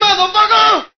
Vox (UGK-2).wav